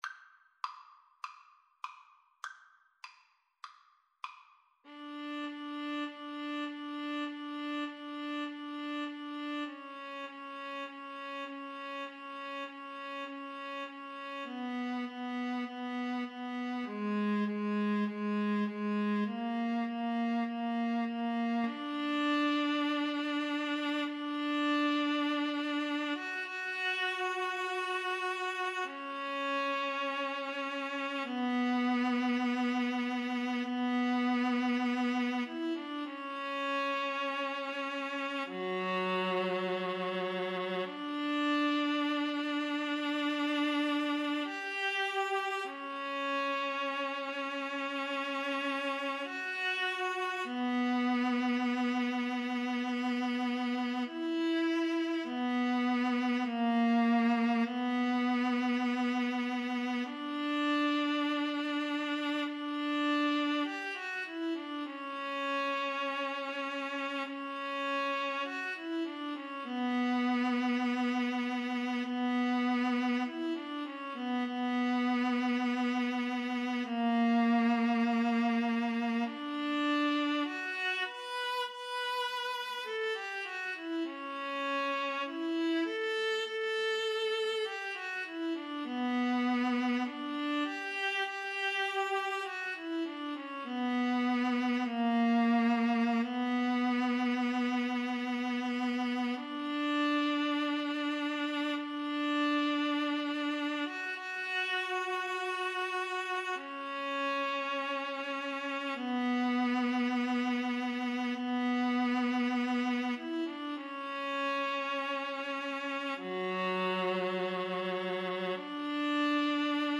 4/4 (View more 4/4 Music)
Classical (View more Classical Viola-Cello Duet Music)